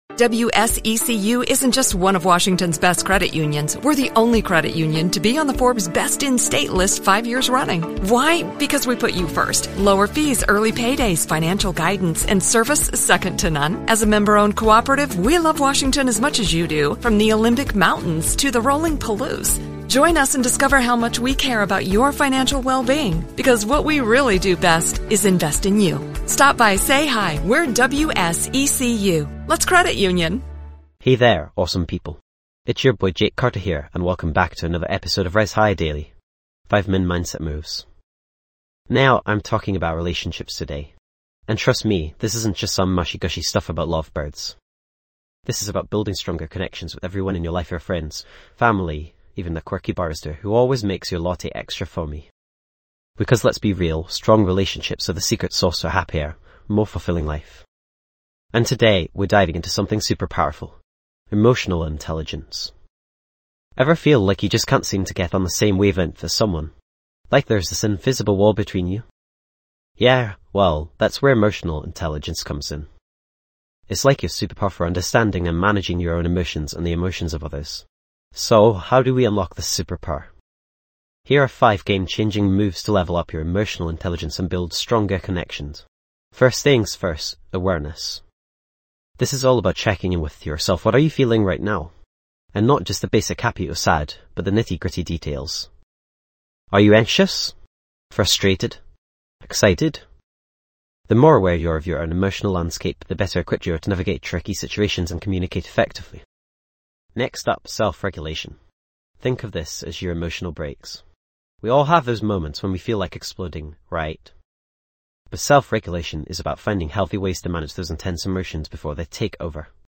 Podcast Category:. Self-Help
This podcast is created with the help of advanced AI to deliver thoughtful affirmations and positive messages just for you.